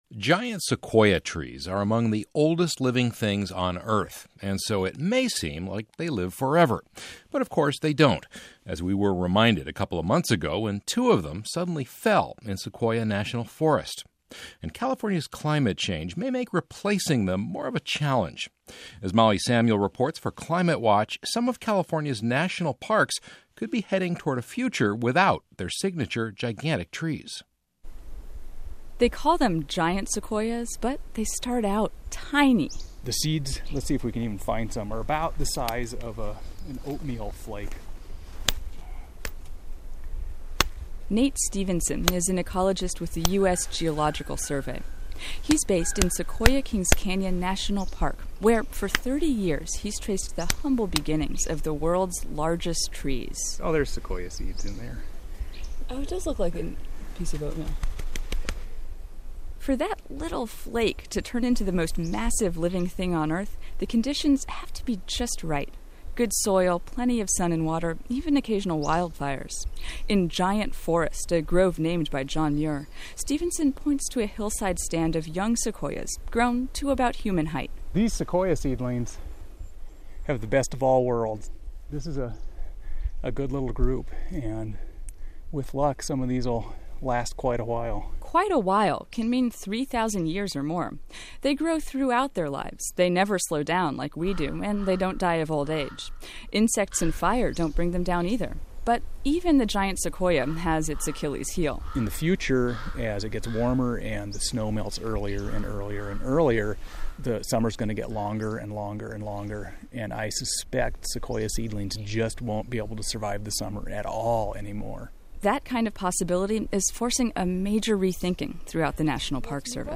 This aired on The California Report on 12/2/11.